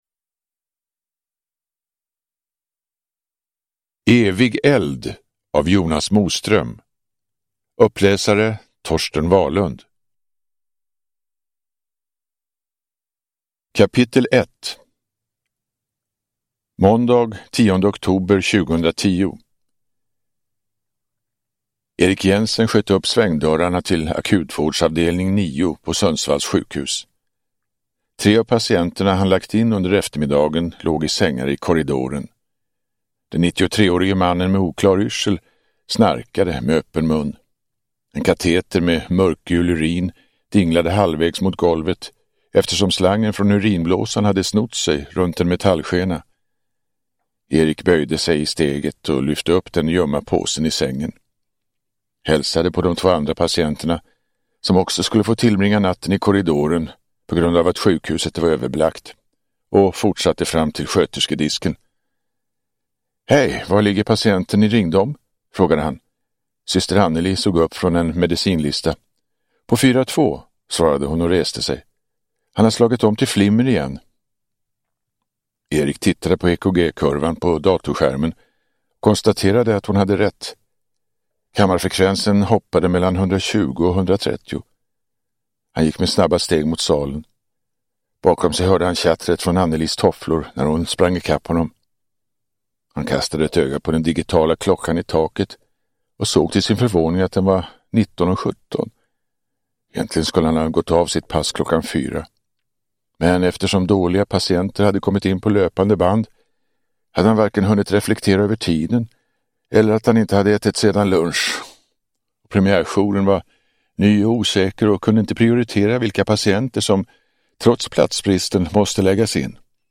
Evig eld – Ljudbok
Deckare & spänning Njut av en bra bok
Uppläsare: Torsten Wahlund